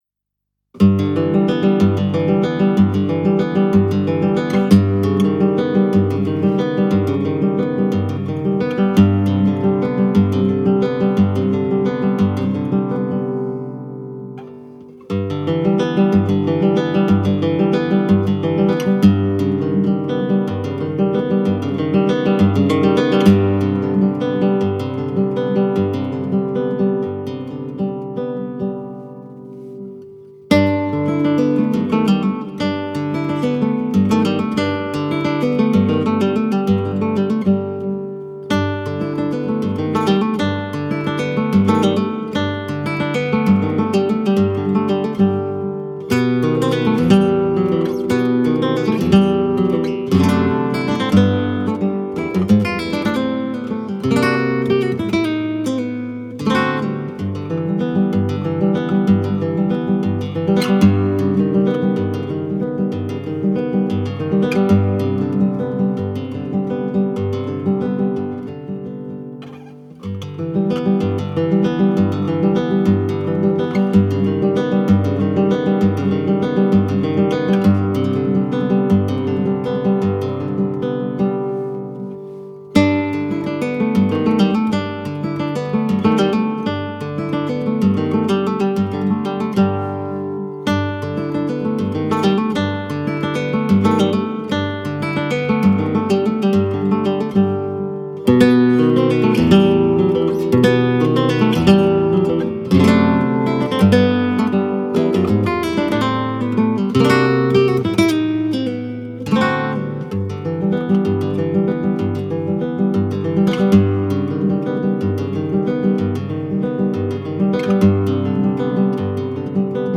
entre musique de salon
composition et guitare